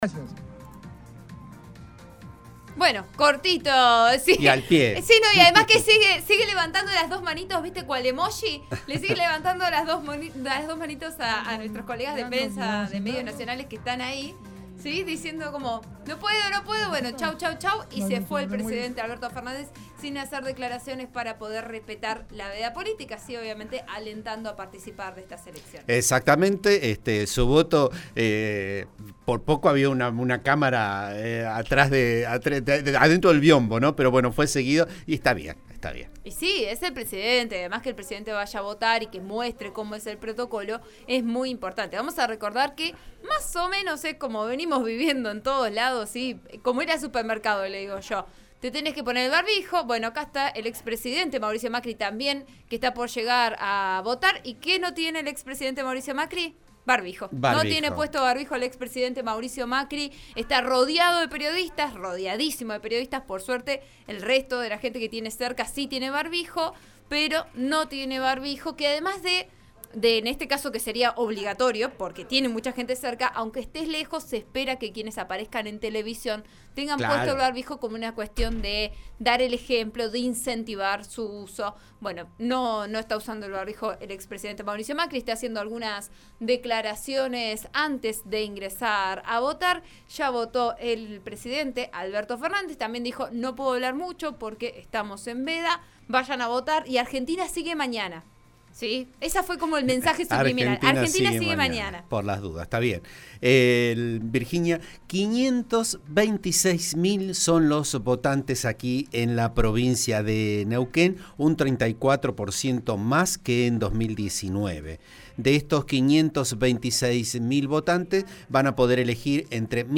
El exgobernador de Neuquén, Jorge Sapag, en declaraciones al programa Vos a Diario, de RN RADIO (89.3), denunció que en algunas escuelas no se les estaba permitiendo a los fiscales sentarse en las mesas de votación.